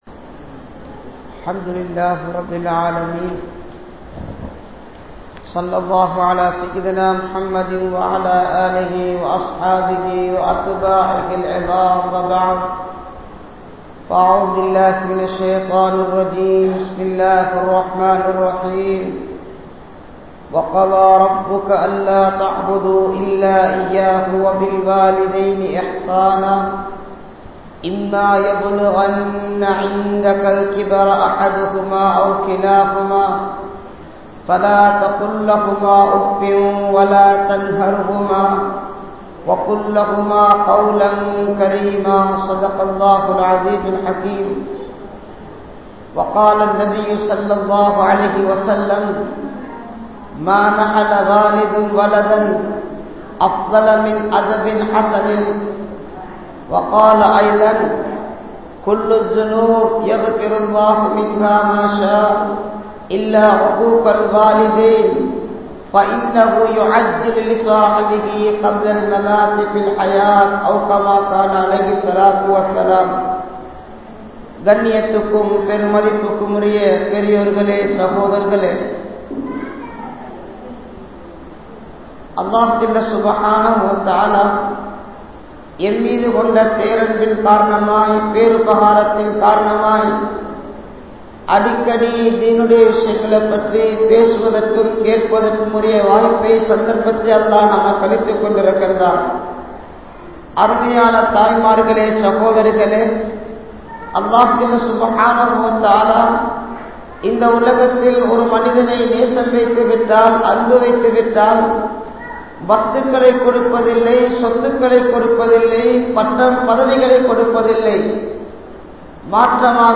Pillai Valarpum Indraya Petroarhalum (பிள்ளை வளர்ப்பும் இன்றைய பெற்றோர்களும்) | Audio Bayans | All Ceylon Muslim Youth Community | Addalaichenai